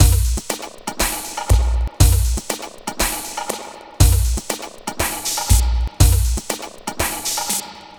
Astro 3 Drumz.wav